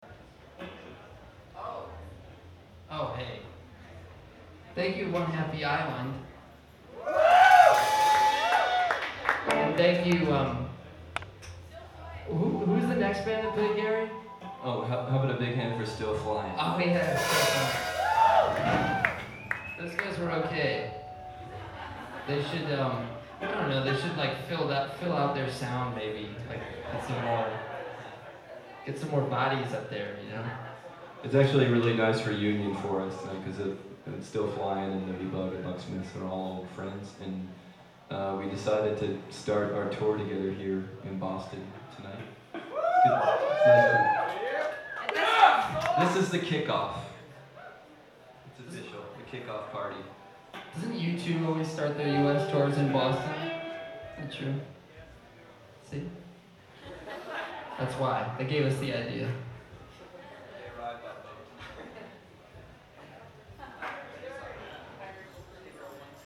Live at TT the Bears
banter